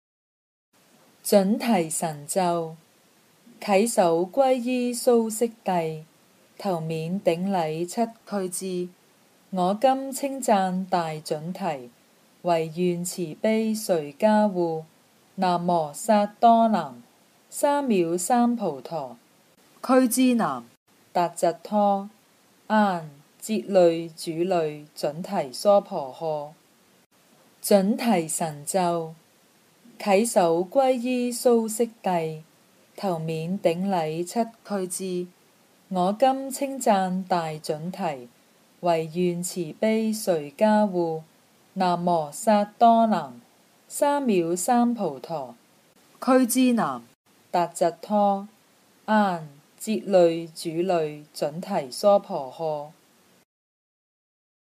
《准提神咒》经文教念粤语版